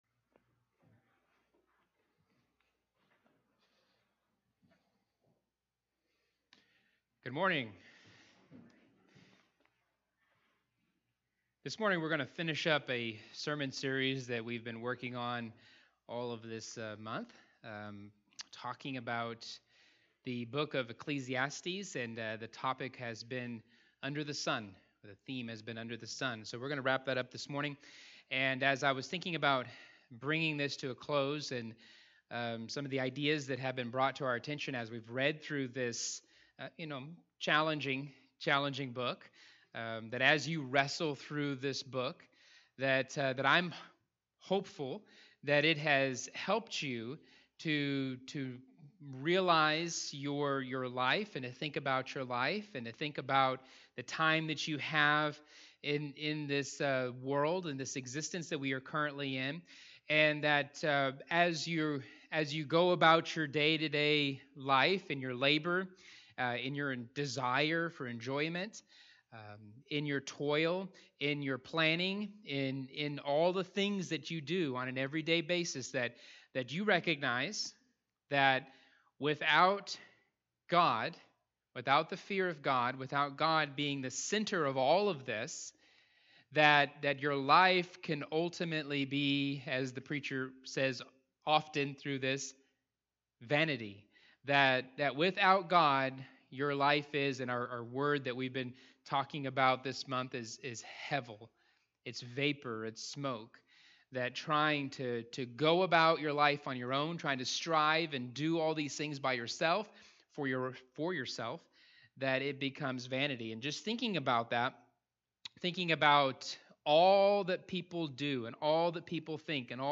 All Sermons Under The Sun